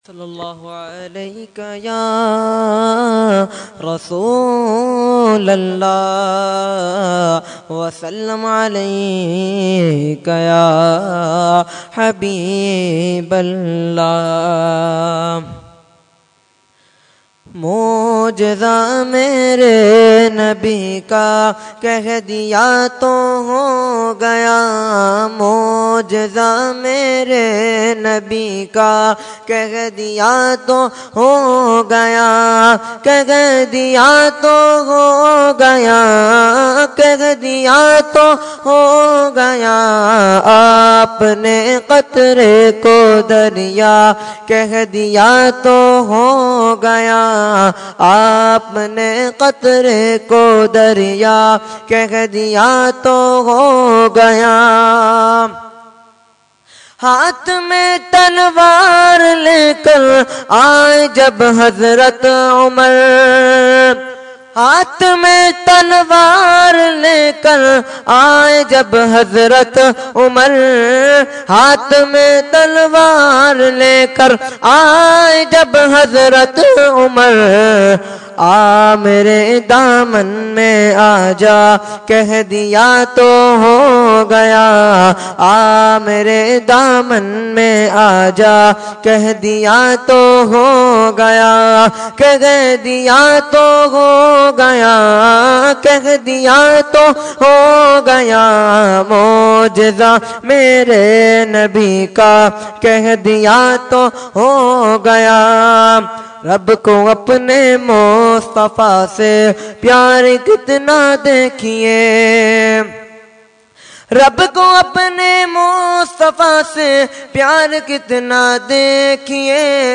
Category : Naat | Language : UrduEvent : 11veen Shareef 2014